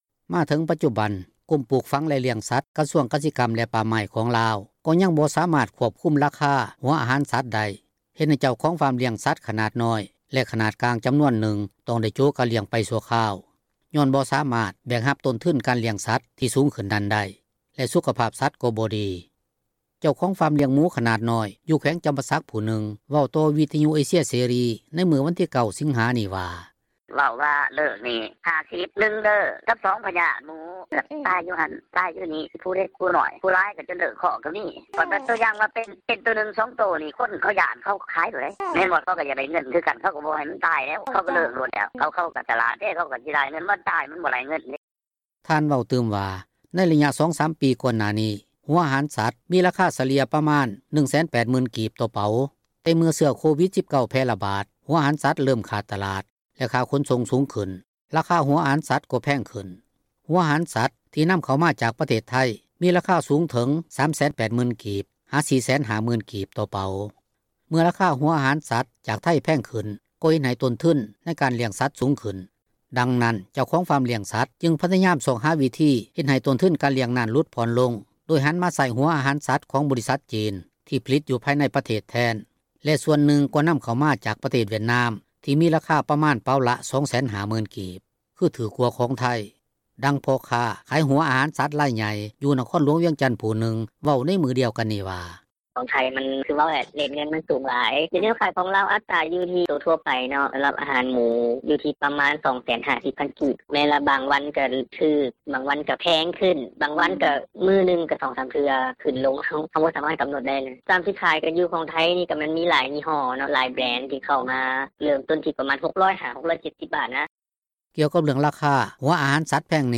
ເຈົ້າຂອງຟາມລ້ຽງໝູຂນາດນ້ອຍ ຢູ່ແຂວງຈໍາປາສັກຜູ້ນຶ່ງເວົ້າຕໍ່ ວິທຍຸເອເຊັຽເສຣີ ໃນມື້ວັນທີ 9 ສິງຫານີ້ວ່າ: